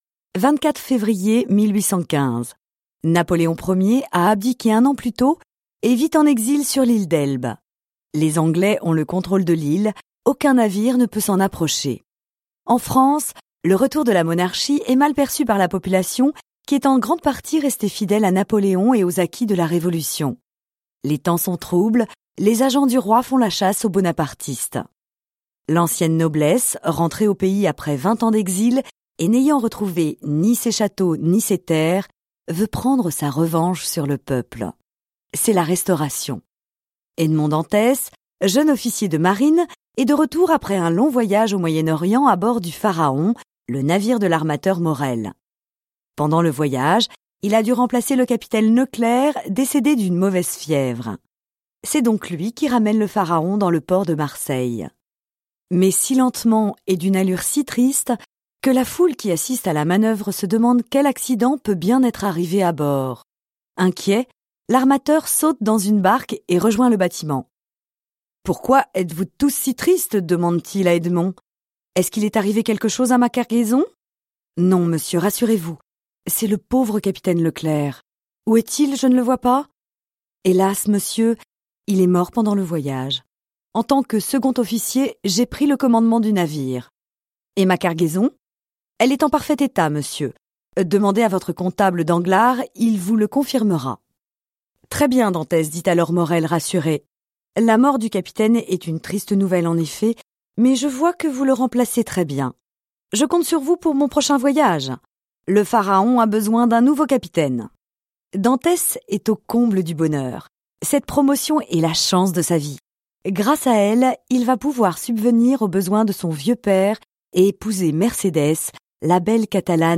Le Comte de Monte Cristo (FR) audiokniha
Ukázka z knihy
• InterpretRôzni Interpreti